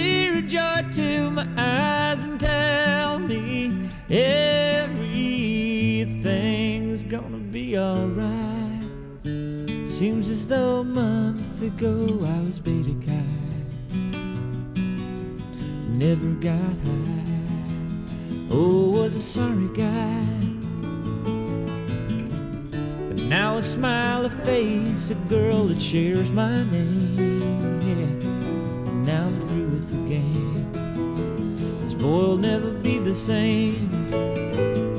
Soft Rock
Жанр: Поп музыка / Рок